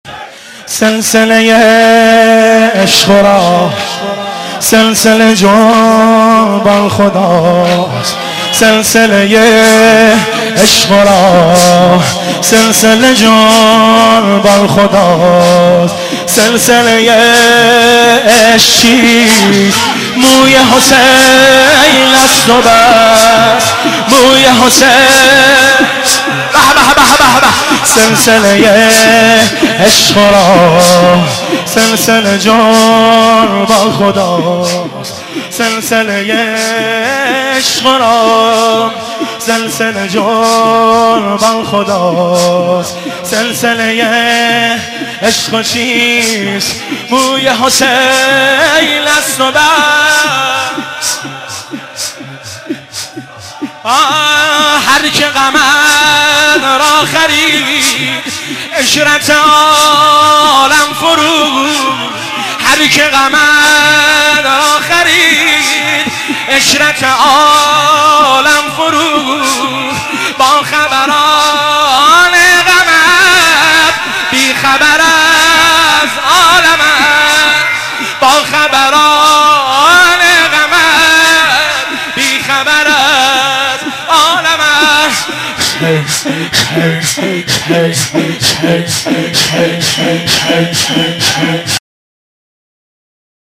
زمینه، روضه، مناجات